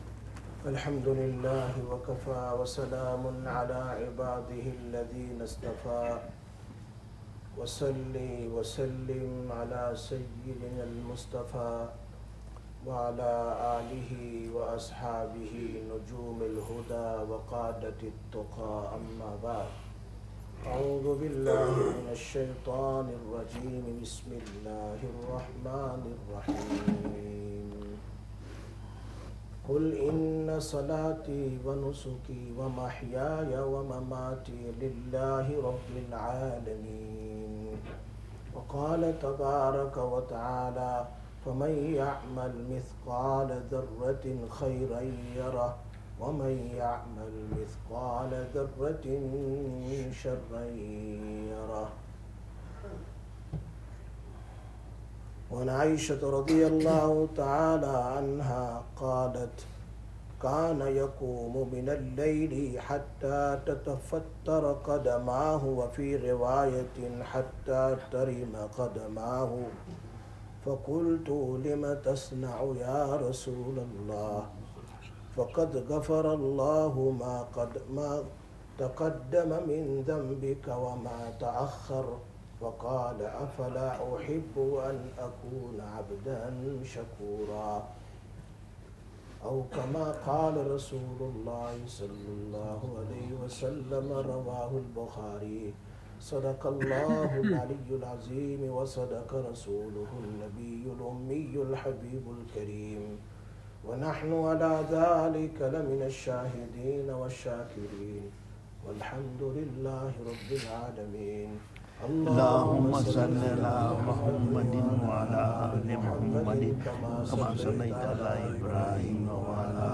15/08/2025 Jumma Bayan, Masjid Quba